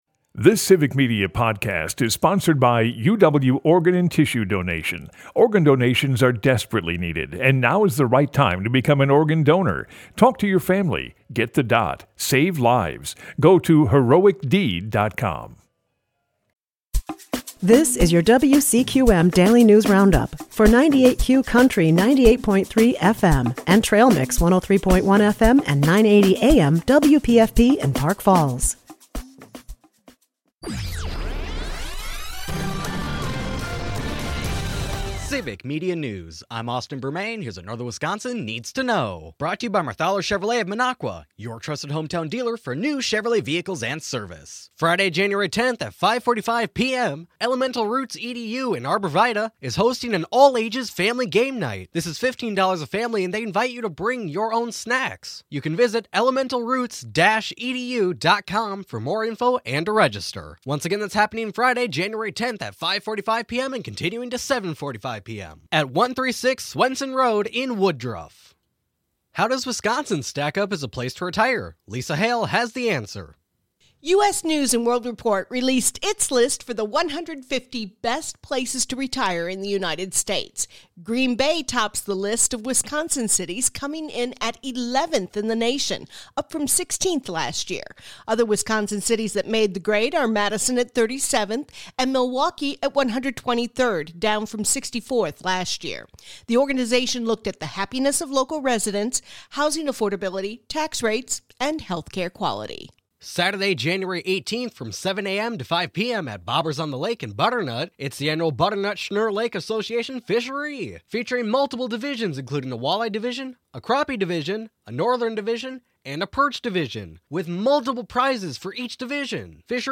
wcqm news